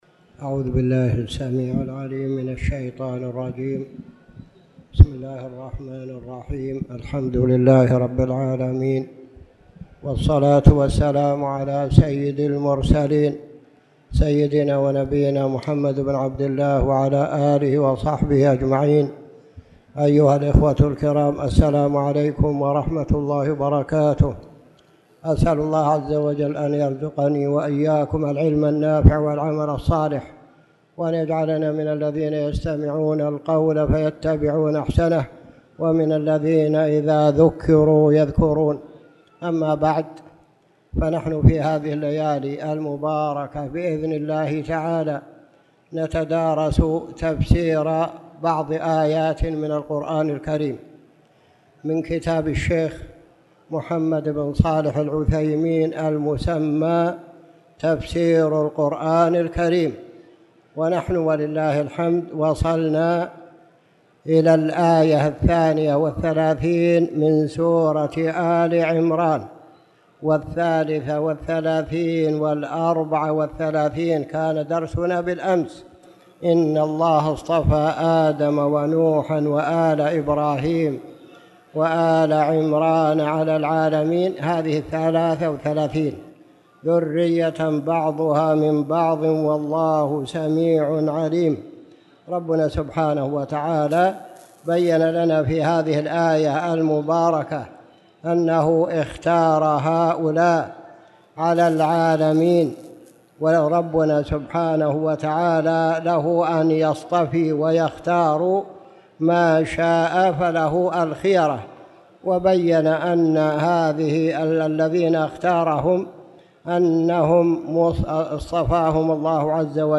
تاريخ النشر ١٠ جمادى الأولى ١٤٣٨ هـ المكان: المسجد الحرام الشيخ